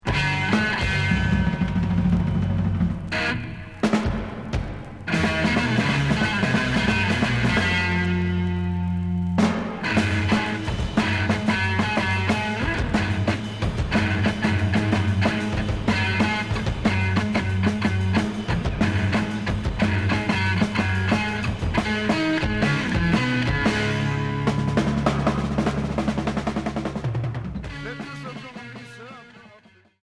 Heavy rock Hendrixien Deuxième 45t retour à l'accueil